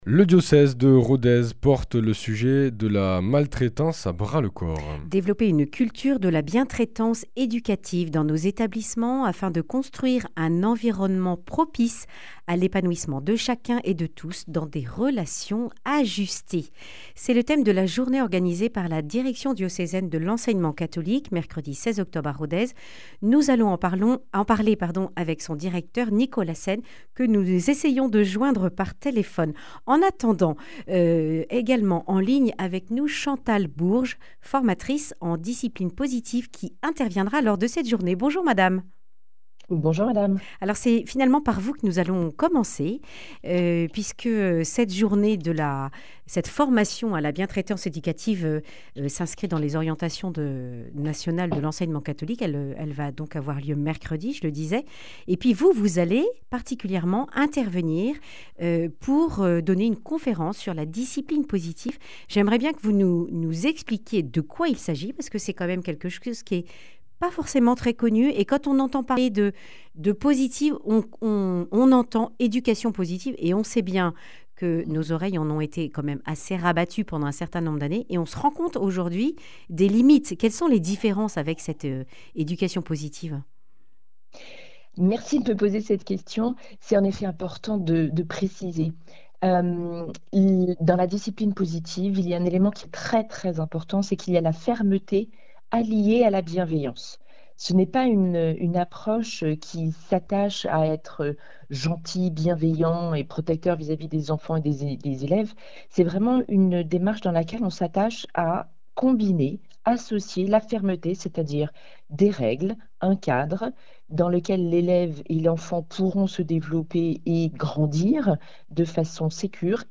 Accueil \ Emissions \ Information \ Régionale \ Le grand entretien \ L’Enseignement Catholique d’Aveyron et du Lot met en place la discipline (...)